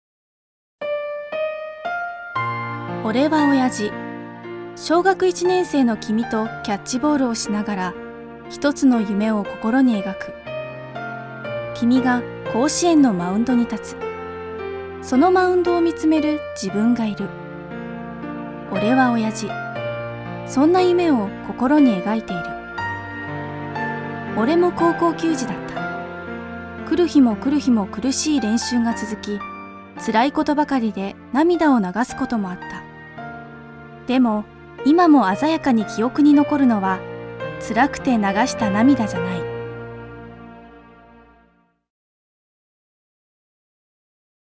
出⾝地・⽅⾔ 大阪府・関西弁
ボイスサンプル